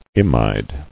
[im·ide]